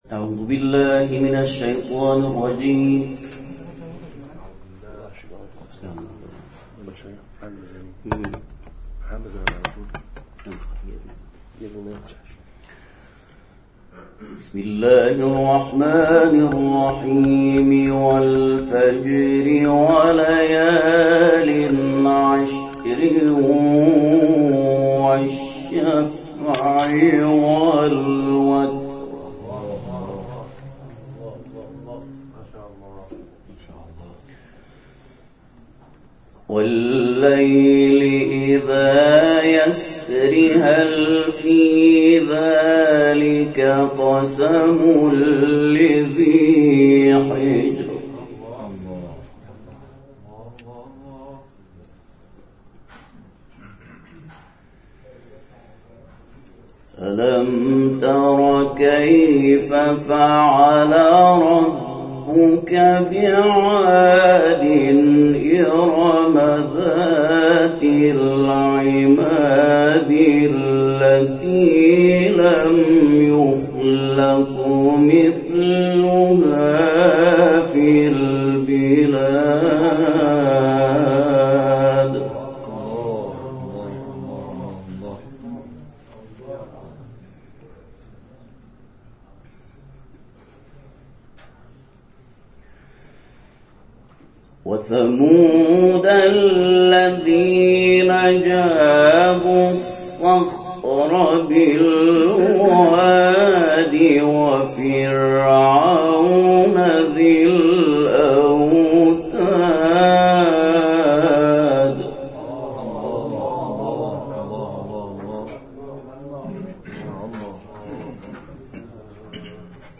گروه جلسات و محافل: همزمان با سومین شب شهادت سرور و سالار شهیدان ابا‌عبدالله الحسین(ع)، مراسم عزاداری همراه با تلاوت قرآن در منزل برادران موسوی‌بلده، با حضور اساتید پیشکسوت و قاریان بین‌المللی و ممتاز کشورمان برگزار شد.